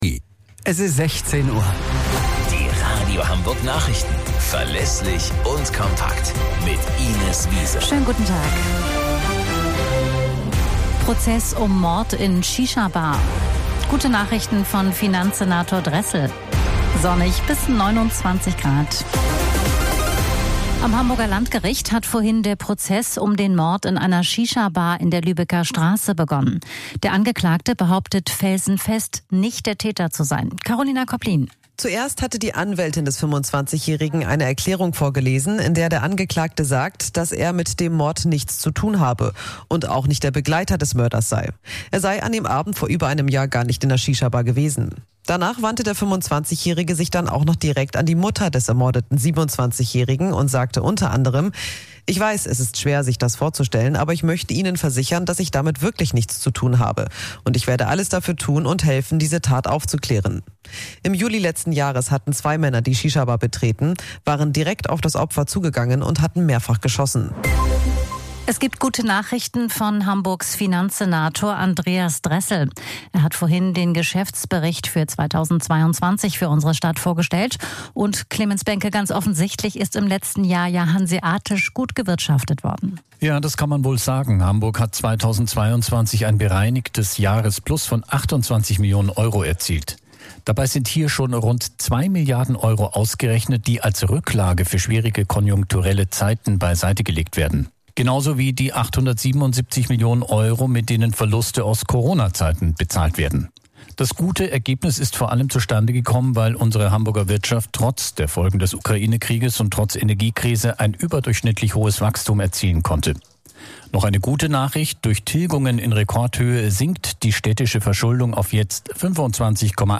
Radio Hamburg Nachrichten vom 05.09.2023 um 16 Uhr - 05.09.2023